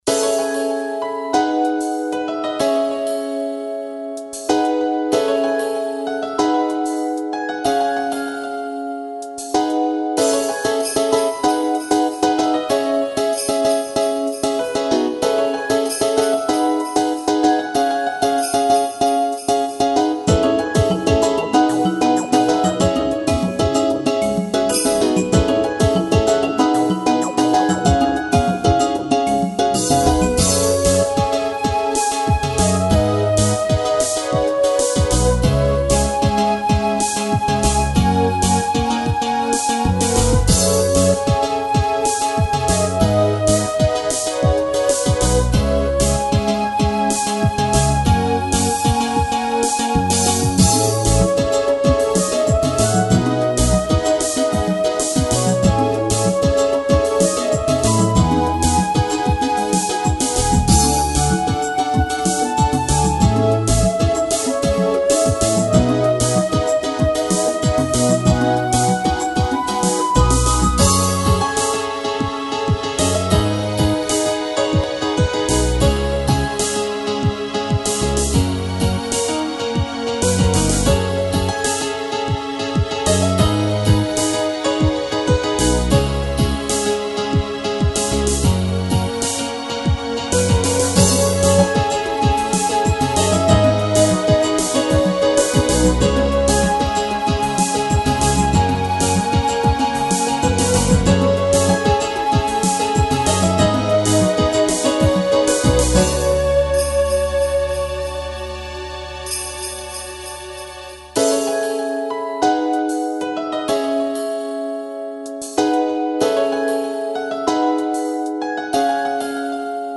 こちらでは、ゲーム内で使われているＢＧＭを何曲か、お聴かせしちゃいます。
不思議な世界にいるイメージです。